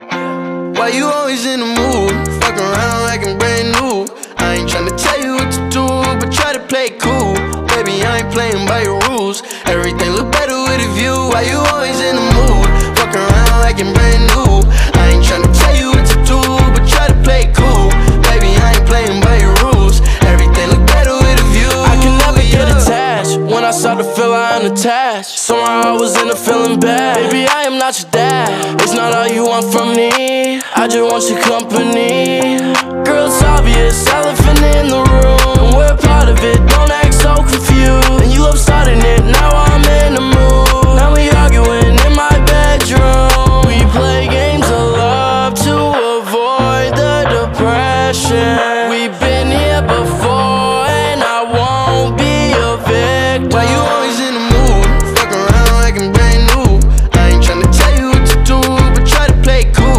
this nostalgic song is so smooth